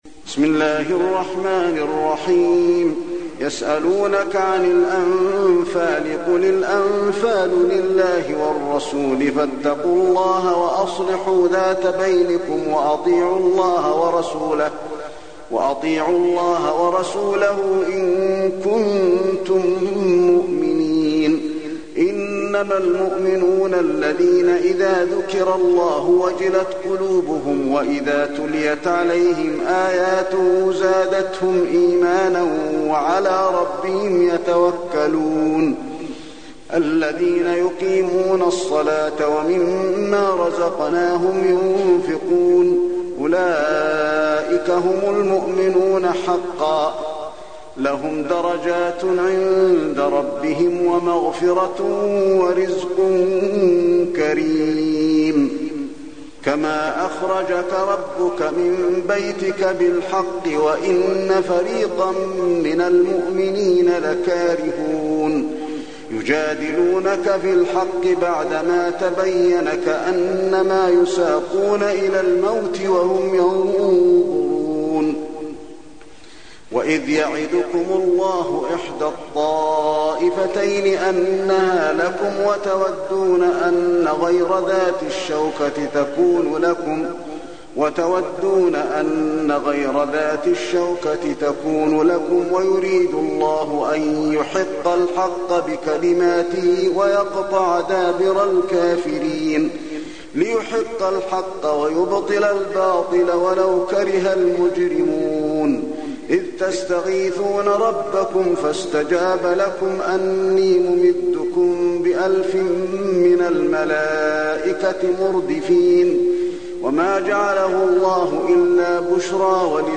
المكان: المسجد النبوي الأنفال